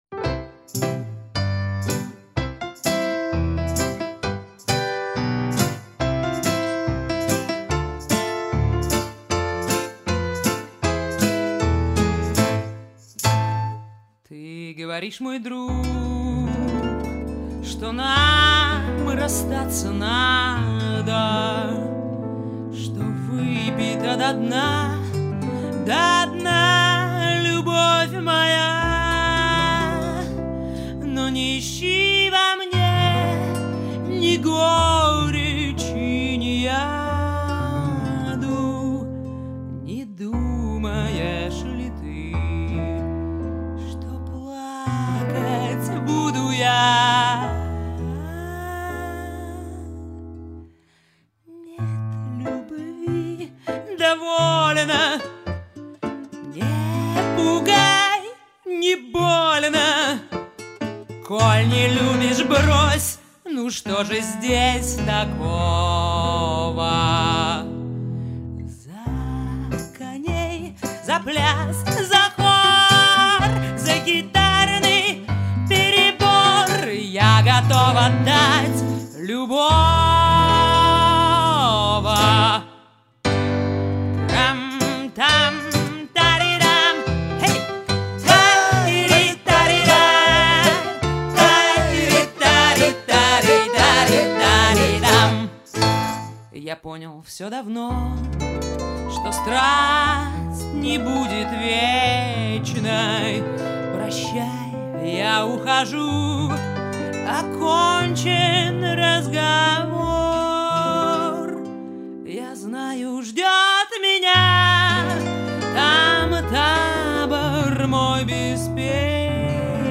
вокал,бубен
рояль
скрипка,гитара,вокал
саксафон